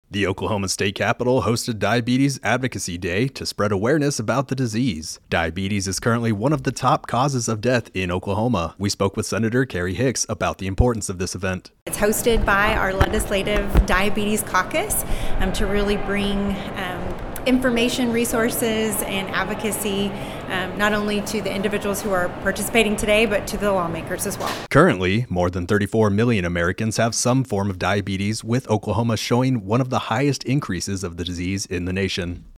spoke with Senator Carrie Hicks about the importance of the event.